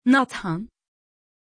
Pronunciation of Nathan
pronunciation-nathan-tr.mp3